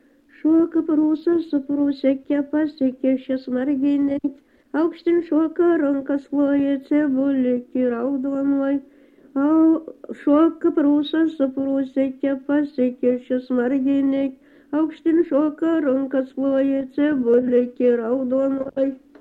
vokalinis
LMTA Mokslo centro muzikinio folkloro archyvas